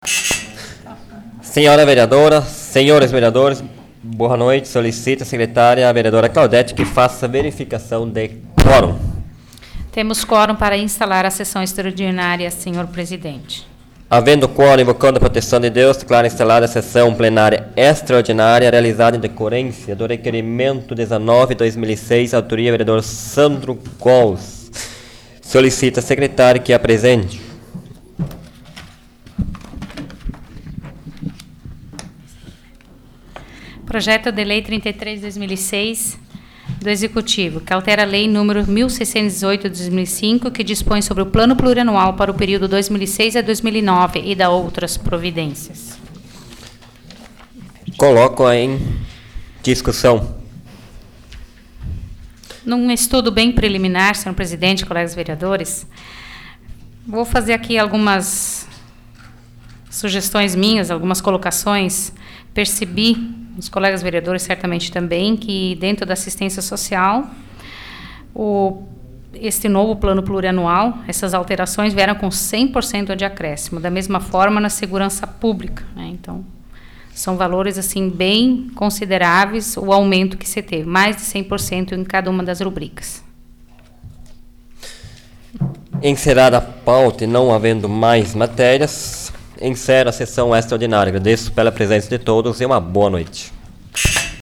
Áudio da 26ª Sessão Plenária Extraordinária da 12ª Legislatura, de 23 de outubro de 2006